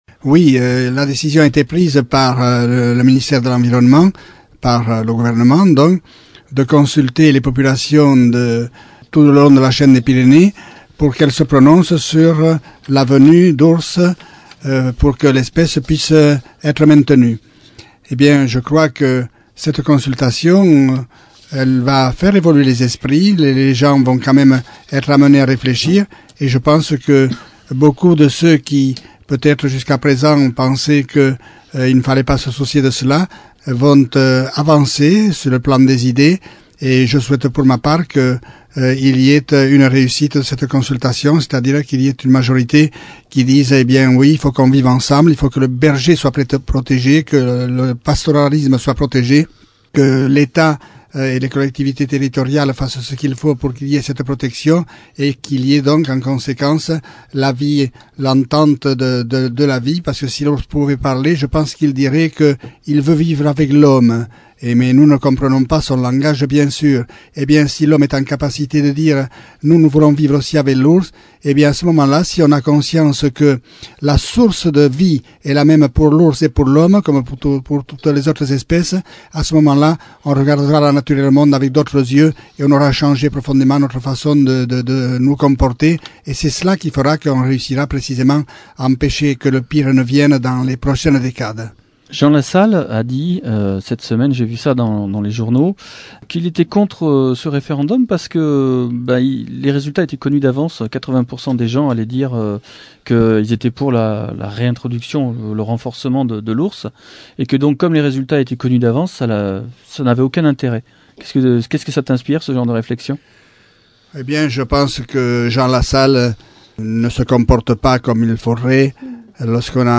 Entzün André Cazetier Mourenx-eko aüzapez ohia bere ikus moldea emaiten :